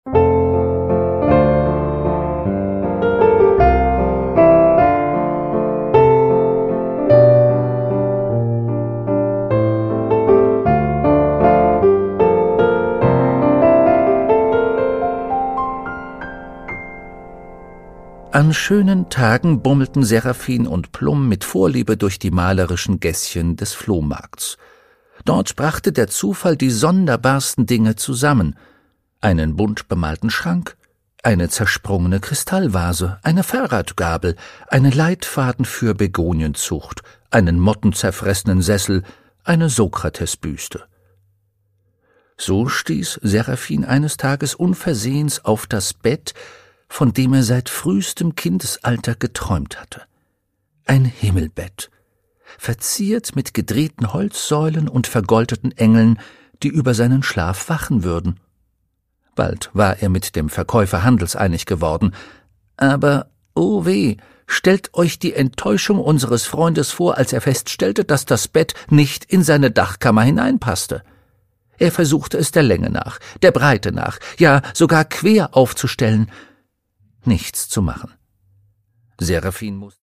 Produkttyp: Hörbuch-Download
Fassung: Ungekürzte Lesung
Gelesen von: Rufus Beck